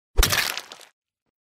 3. Звук смерти амонг ас
3-zvuk-smerti-among-as.mp3